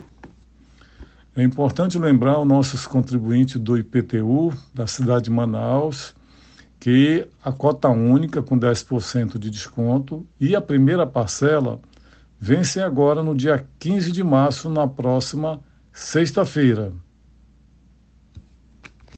SONORA_SUBSECRETARIO-Arminio-Pontes.m4a